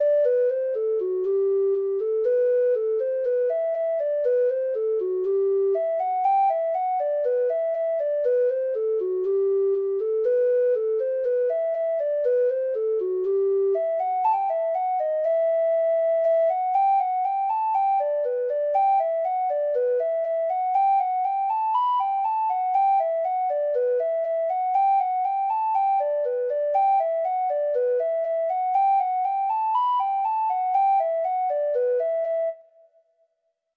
Free Sheet music for Treble Clef Instrument
Traditional Music of unknown author.
Reels
Irish